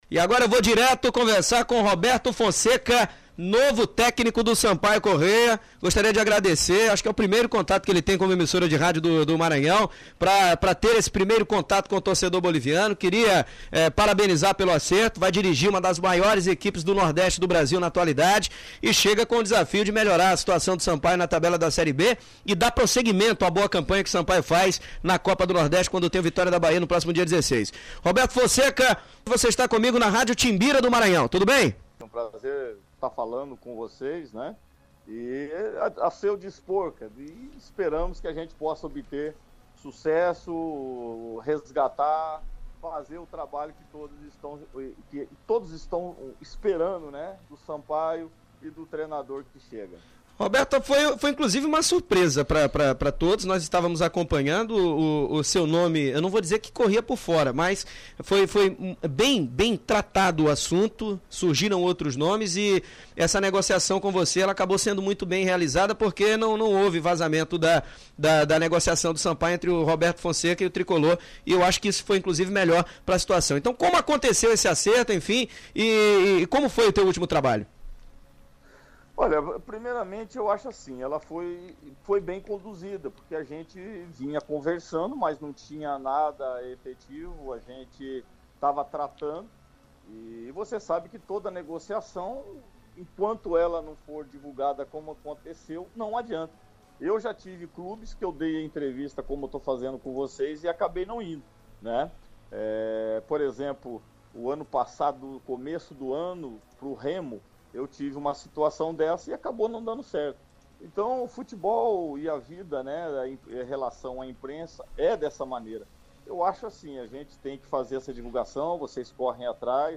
O Blog reproduz a entrevista na íntegra.